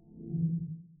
creatures_ghost_death.ogg